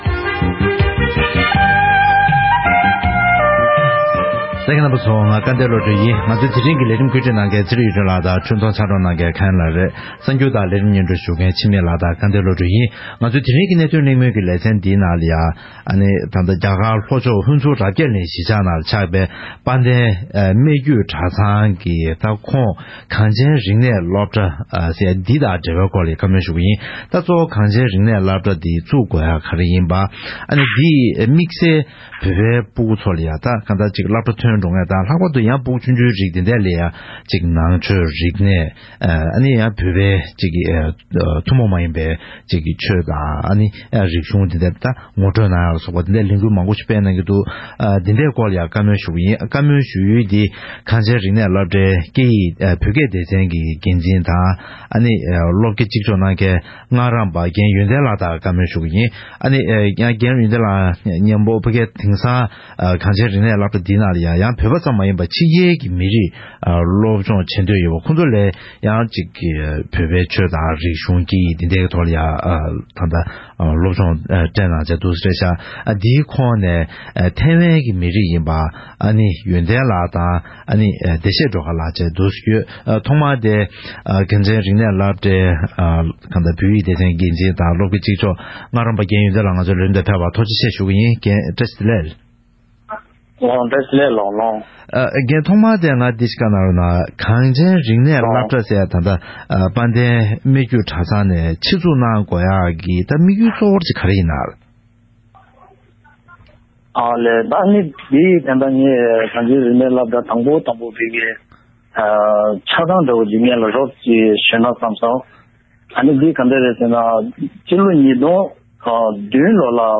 རྒྱ་གར་ལྷོ་ཕྱོགས་སུ་རྟེན་གཞི་བྱེད་པའི་གངས་ཅན་རིག་གནས་སློབ་གྲྭའི་ལོ་རྒྱུས་དང་ལས་དོན་སོགས་དང་འབྲེལ་བའི་སྐོར་གླེང་མོལ།